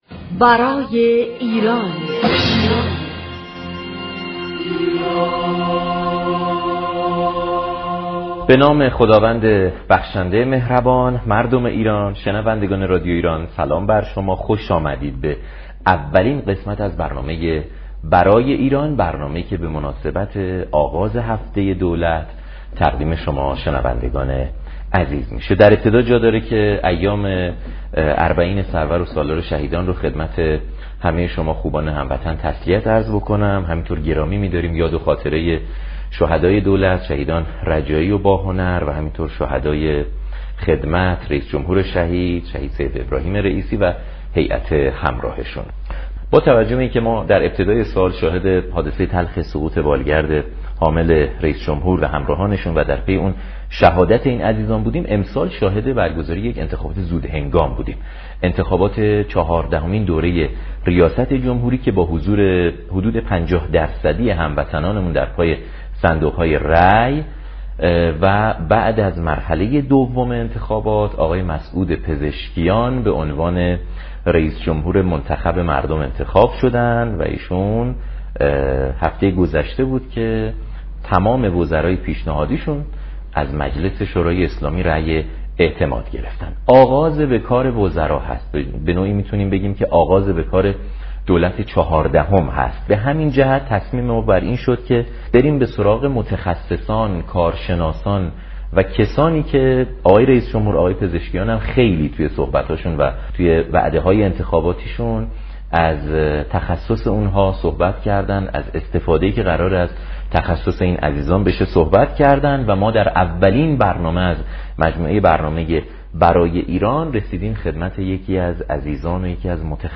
رییس اسبق سازمان سینمایی ایران در برنامه برای‌ایران گفت:هر چقدر بتوانیم سرانه مصرف فرهنگی را در حوزه‌هایی چون كتاب، سینما، تئاتر، موسیقی بالا ببریم؛ این میزان افزایش نقش مهمی را در توسعه‌یافتگی كشور خواهد داشت.